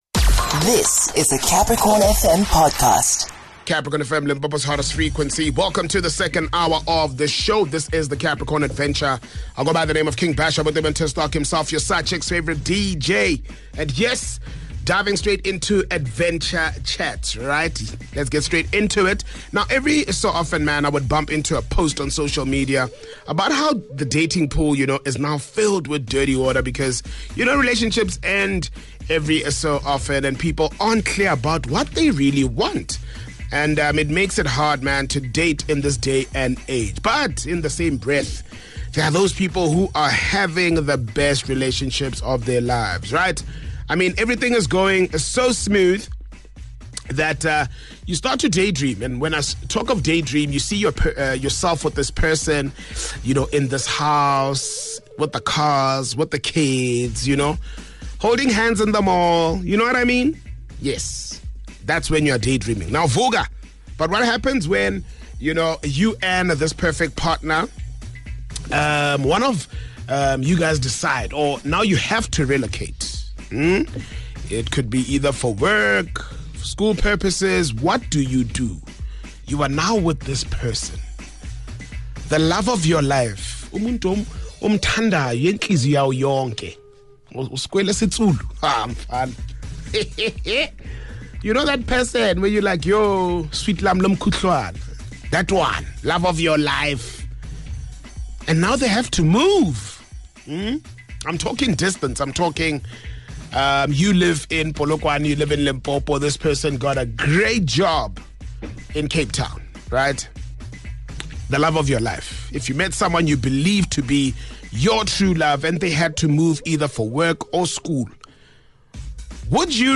The adventure family from far and wide come on air and share their experiences and how they feel they would tackle this situation should they find themselves in it.